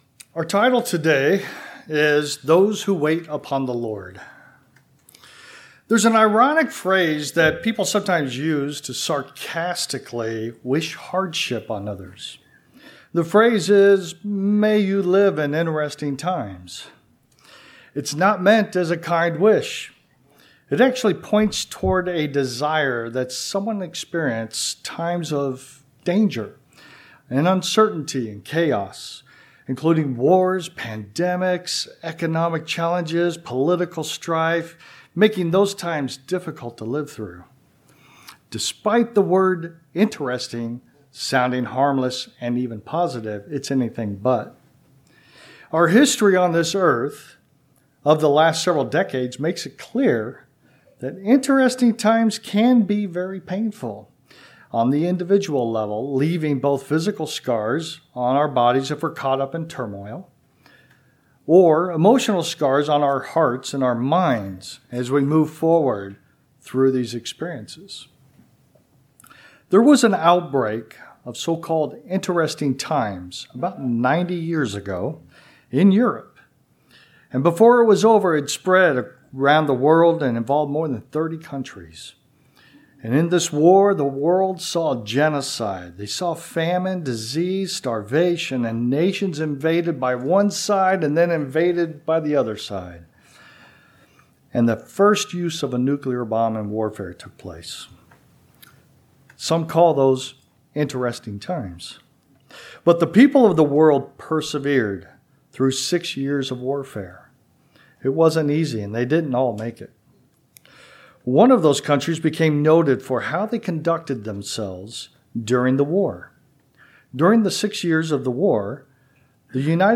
Given in Chicago, IL